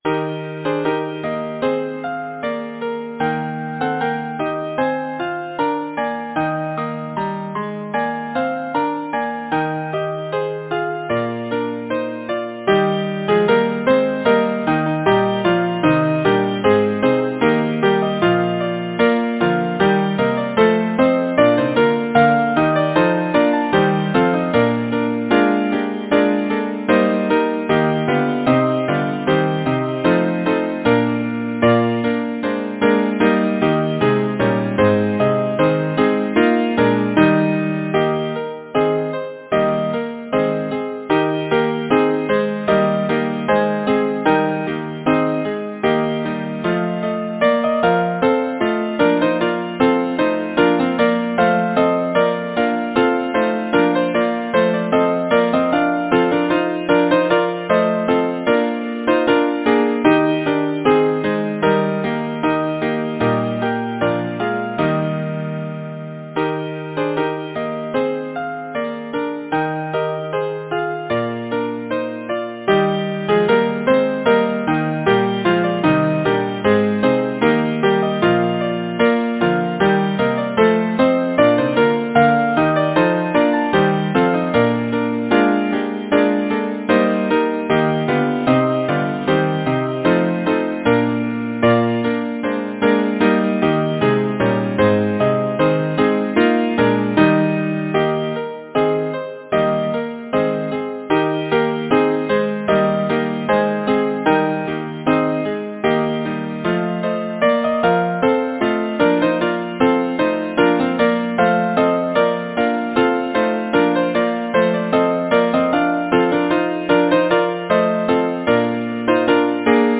Title: Evening Bells Composer: Edward Bunnett Lyricist: Edward Oxenford Number of voices: 4vv Voicing: SATB Genre: Secular, Partsong
Language: English Instruments: Piano